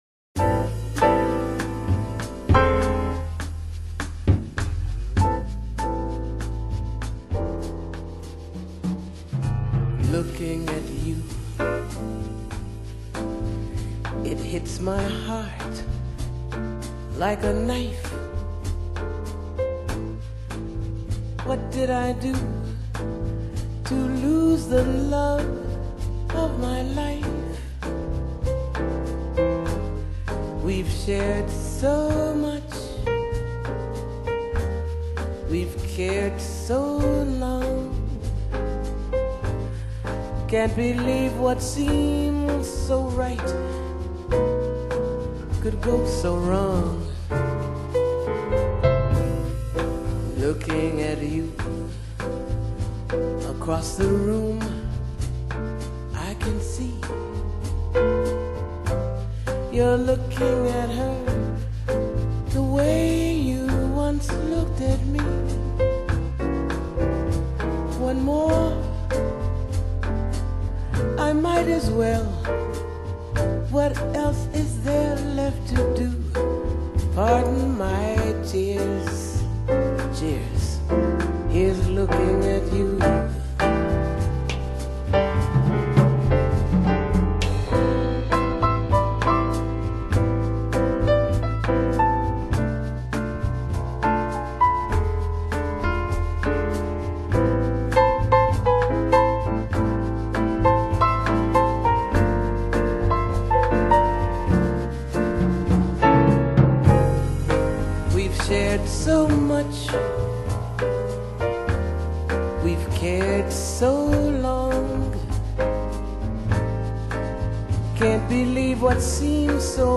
vocals, piano, Roland electric piano